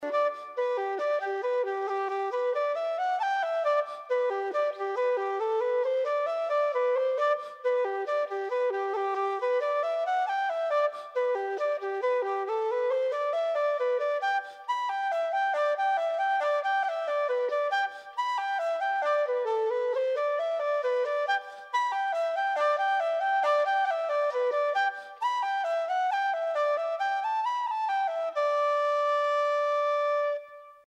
Piano
Tune book recording